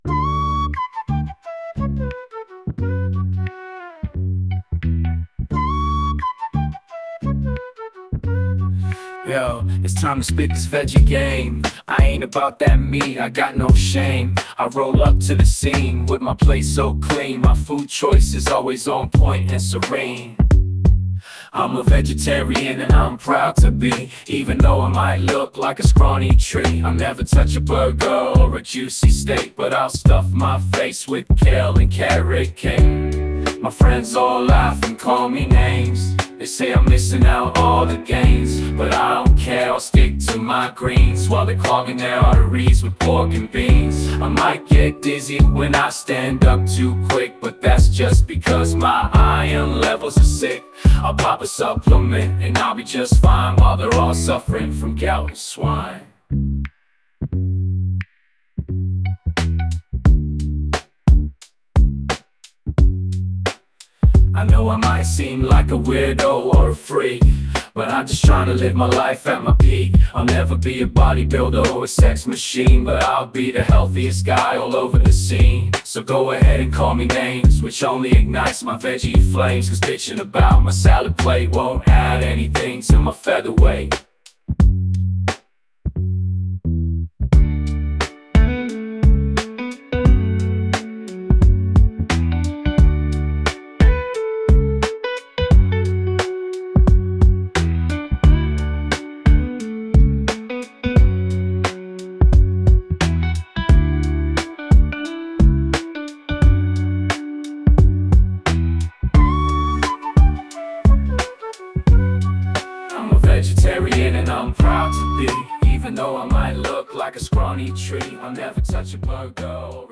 Finally, I made some minor tweaks to the music (mainly responsible for the little imperfections you might hear) with the help of audacity.
Version 3: Music by Suno (V3)